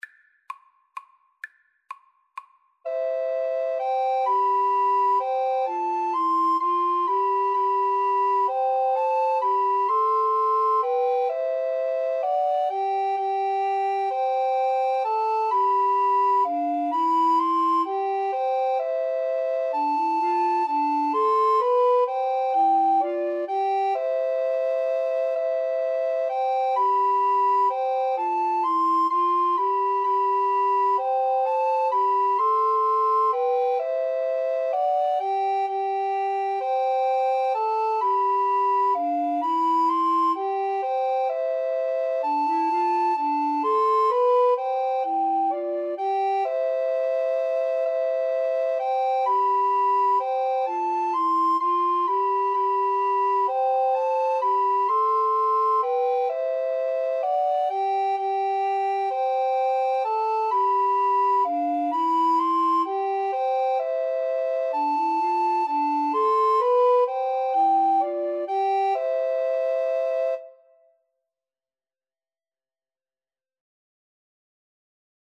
Soprano RecorderAlto RecorderTenor Recorder
C major (Sounding Pitch) (View more C major Music for Recorder Trio )
3/4 (View more 3/4 Music)
quem_pastores_SATRC_kar1.mp3